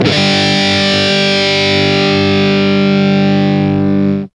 Index of /90_sSampleCDs/Roland L-CD701/GTR_Distorted 1/GTR_Power Chords